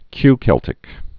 (kykĕltĭk, -sĕl-)